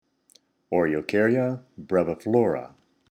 Pronunciation/Pronunciación:
O-re-o-cár-ya  bre-vi-flò-ra